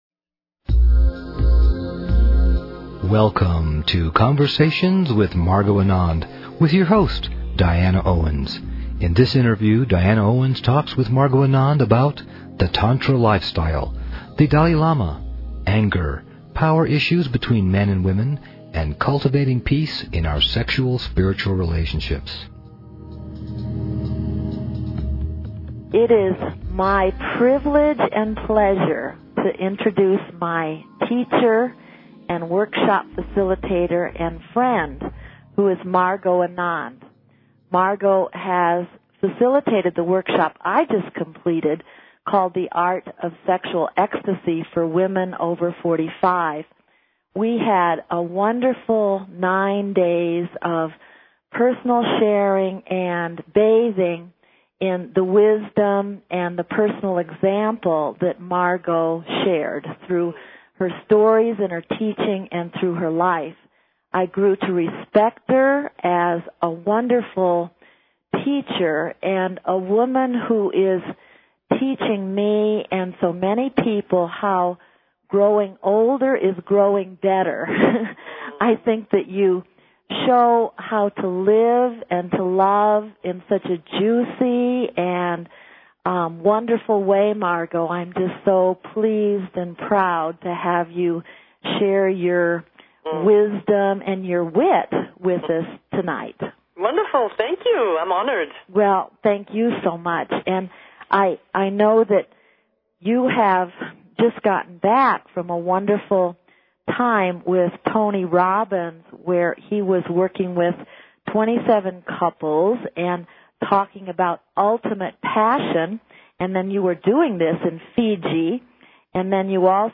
Crecimiento Personal -Conversaciones con Margot Anand Secrets of a Great Love Life-